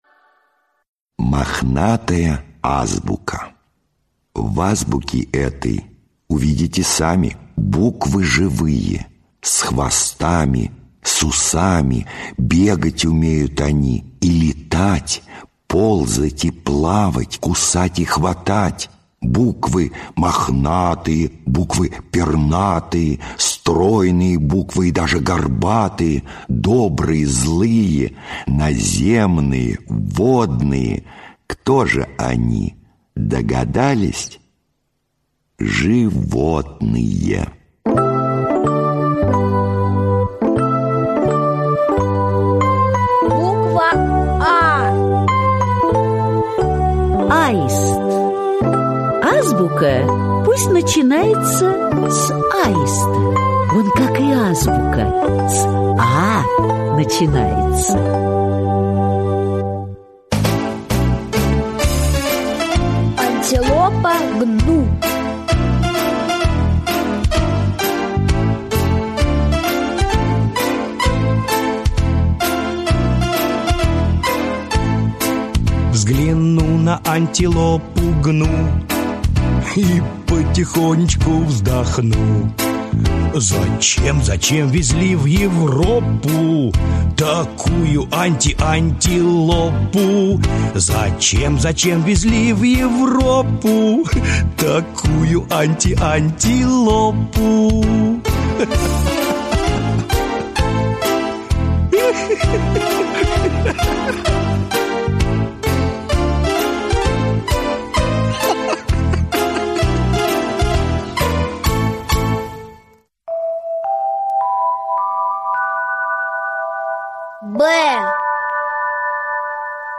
Мохнатая азбука — слушать аудиосказку Борис Заходер бесплатно онлайн